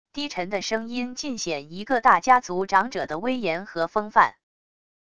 低沉的声音尽显一个大家族长者的威严和风范wav音频